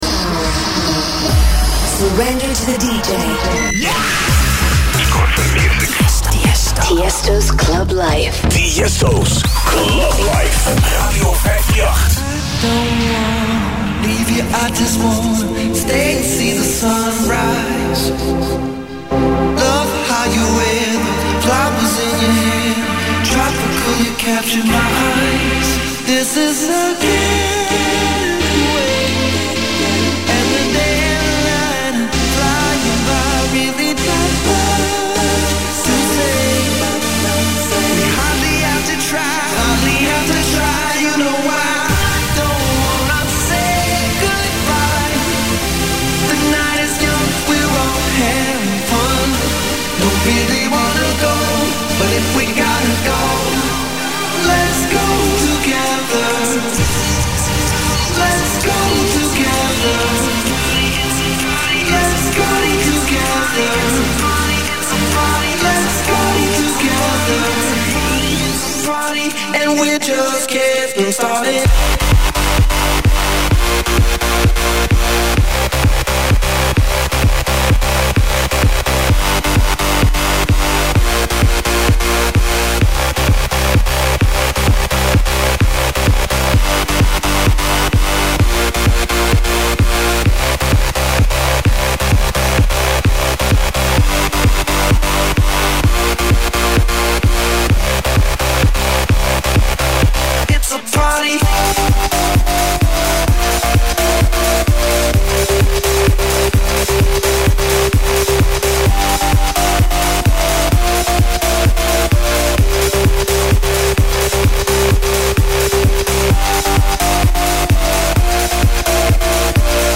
Категория: Club & Dance